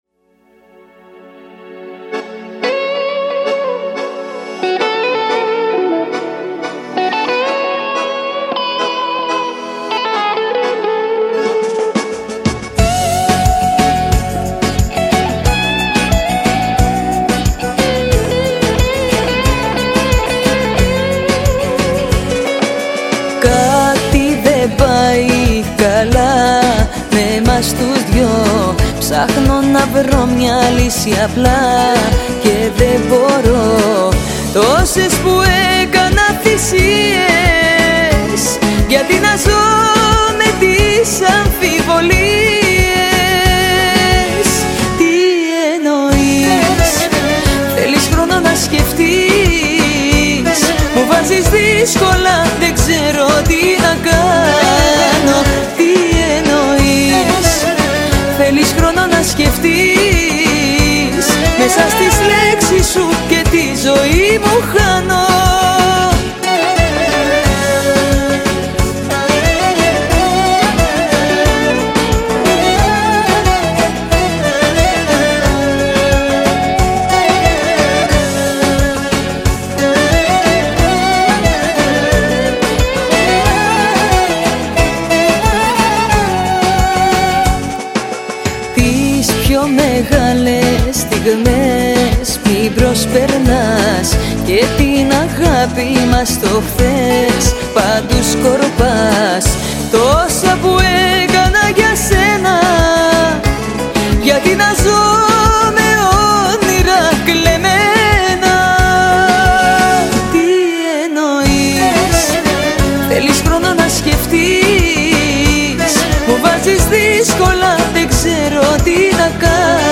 καλοκαιρινή διάθεση
ρυθμικό τραγούδι